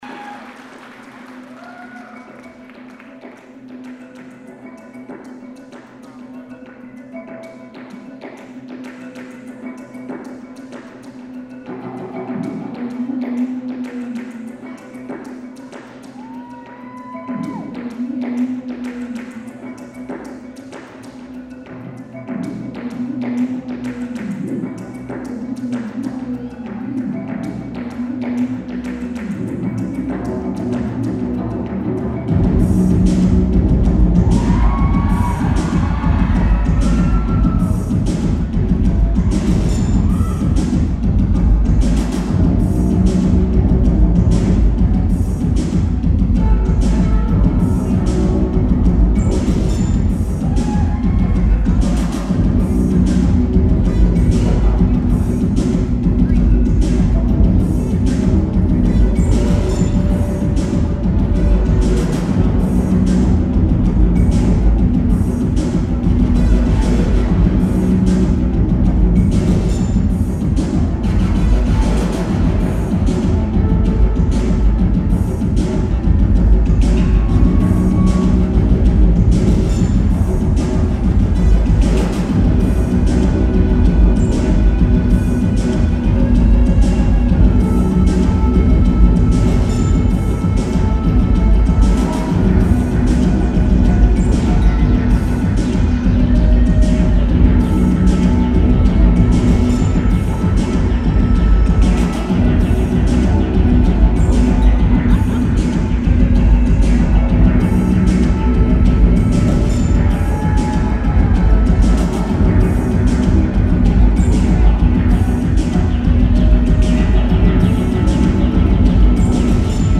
The Golden State Theatre
Lineage: Audio - AUD (AT-831s + SP-SB1-SL + Tascam DR-05)